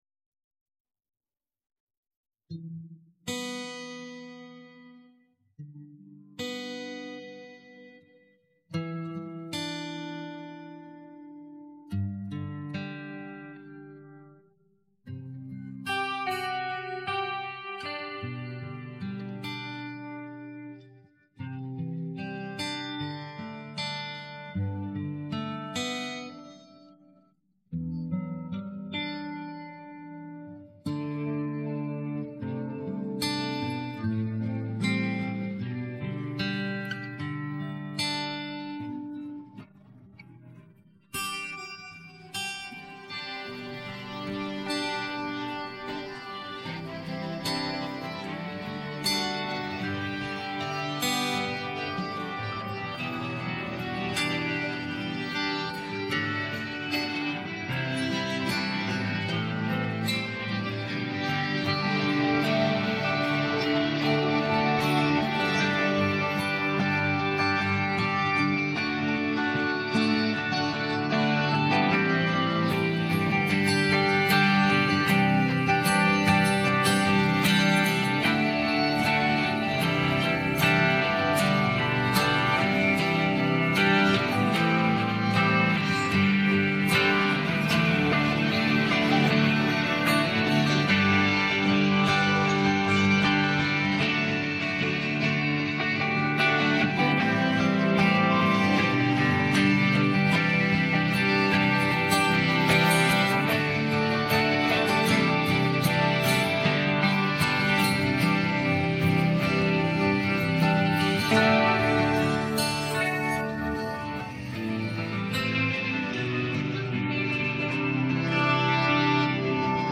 beautiful guitar